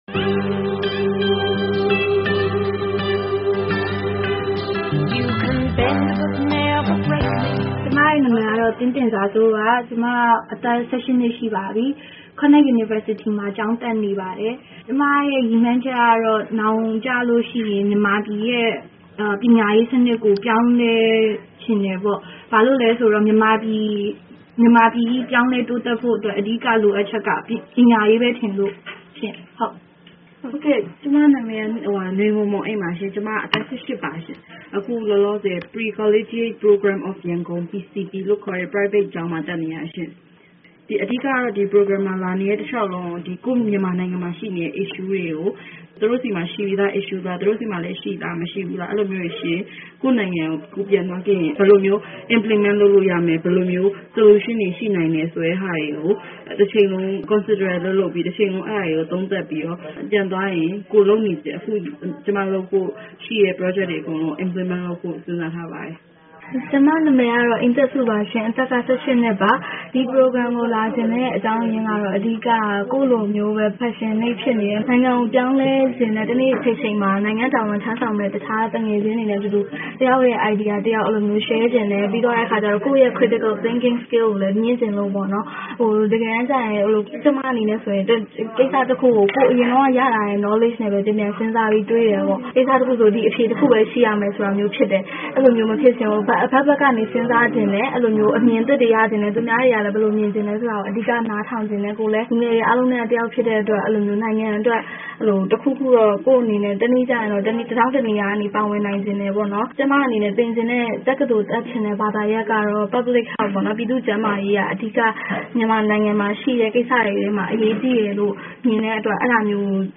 အမေရိကန်နိုင်ငံခြားရေးဝန်ကြီးဌာနရဲ့ နိုင်ငံတကာအစီအစဉ်တခုဖြစ်တဲ့ Myanmar Youth Leadership Program (MYLP) လူငယ်ခေါင်းဆောင်မှု အရည်အသွေး မြှင့်တင်ရေး အစီအစဉ်နဲ့ ရောက်နေတဲ့ အမျိုးသမီးငယ်သုံးဦးရဲ့ အမြင်တွေနဲ့ပတ်သက်လို့ မေးမြန်းထားတာကို